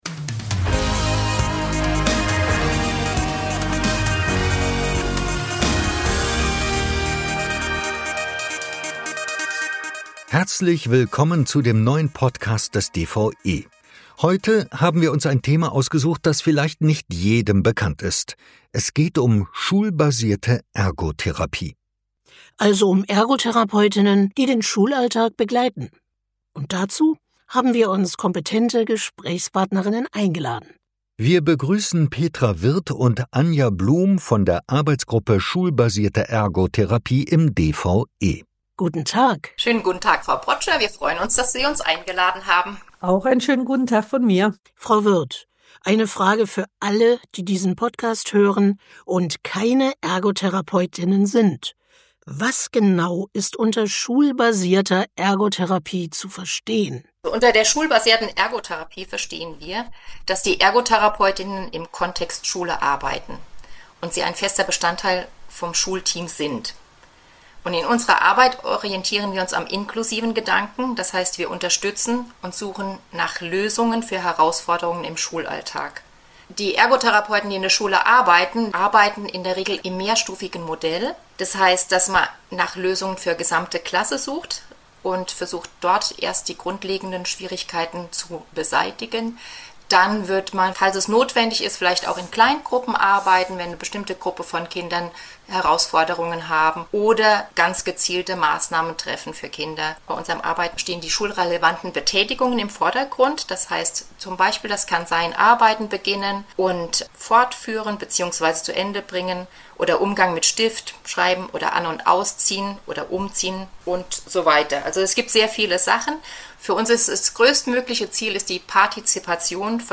Erfahren Sie diesmal, welche Rolle Ergotherapeut:innen im Schulalltag in einem interdisziplinären Team zukommt und wie die Arbeit abläuft? Unsere Gesprächspartnerinnen sind die...